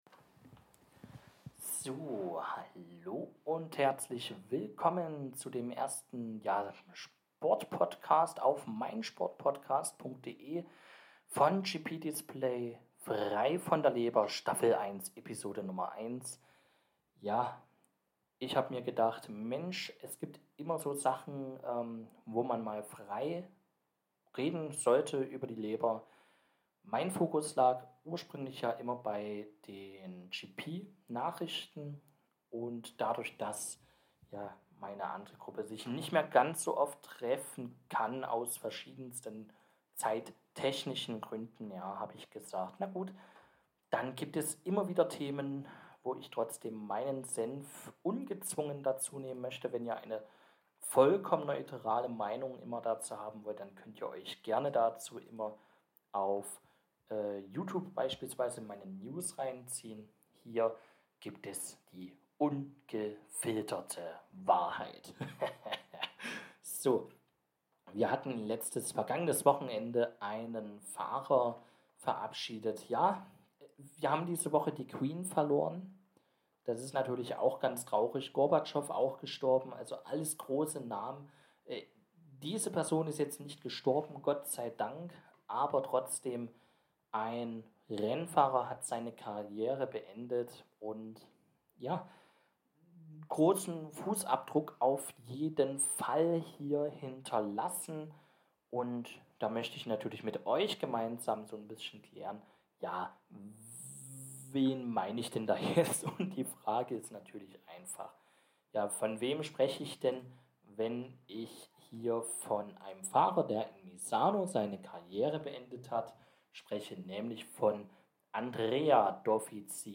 Die wöchentliche Zusammenfassung im Audioformat zur Motorradsportwelt – live und ohne Skript frei von der Leber gesprochen, mit Gedanken und Wörtern zu derzeitigen Situationen. Im Gespräch natürlich nicht nur MotoGP, sondern und vor allem auch ein Blick auf die nationalen Serien.